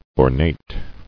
[or·nate]